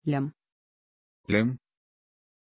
Pronunciation of Lyam
pronunciation-lyam-ru.mp3